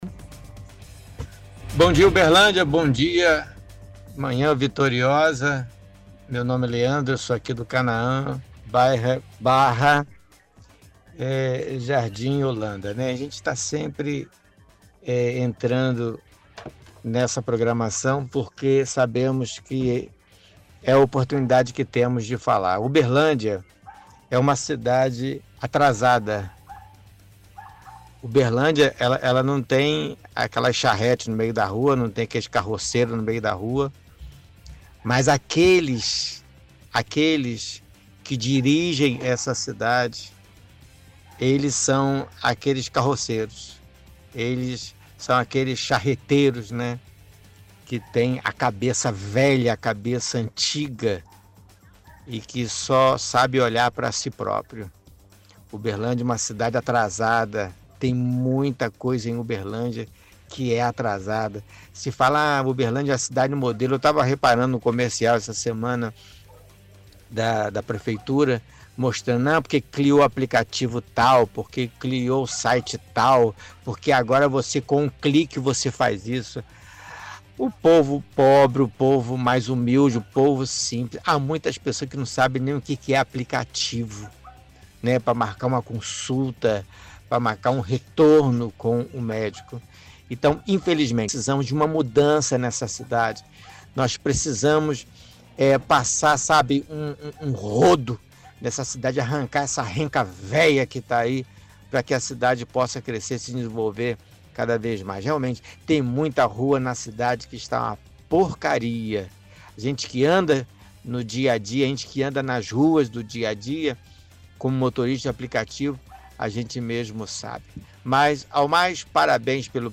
– Ouvinte do bairro Canaã diz que Uberlândia é uma cidade atrasada.